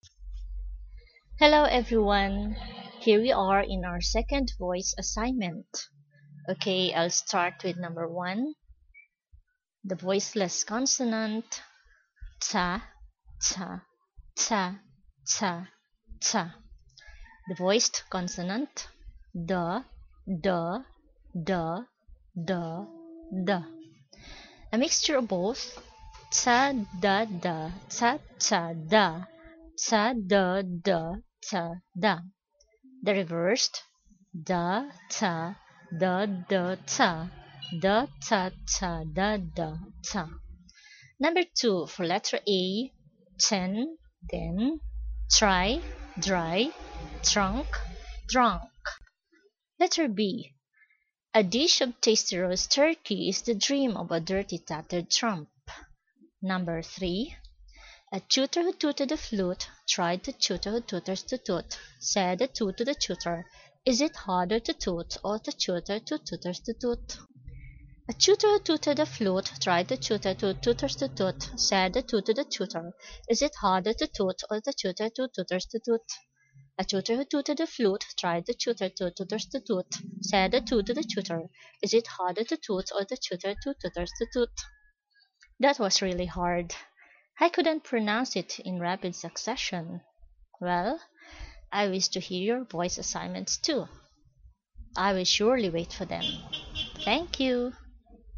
/t/ and /d/ sounds
phonemes